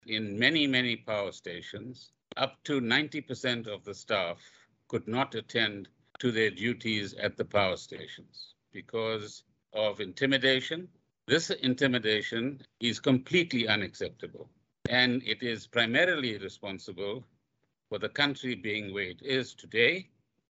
Gordhan yesterday, during a press briefing, announced that a wage agreement was reached yesterday between unions and Eskom.